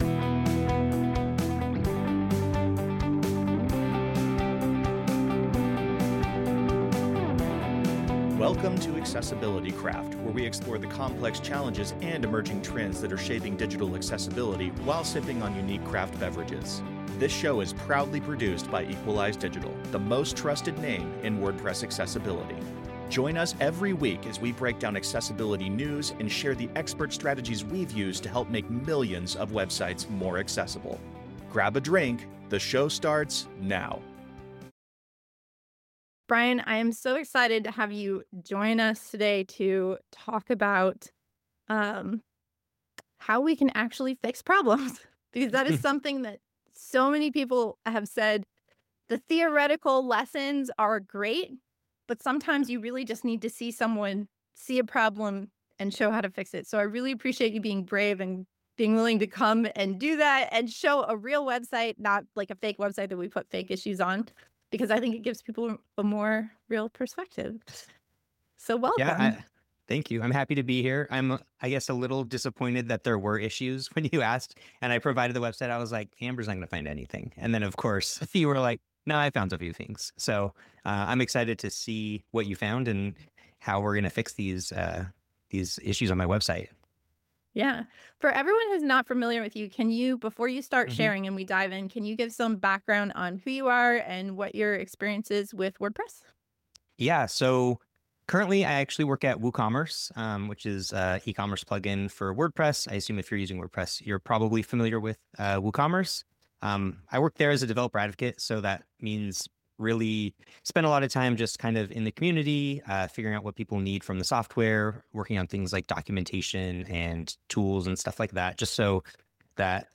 This episode is a recording of a December 2025 WordPress Accessibility Meetup, brought to you by Equalize Digital.
WordPress Accessibility Meetups are a 100% free and virtual community resource that take place via Zoom webinars twice a month.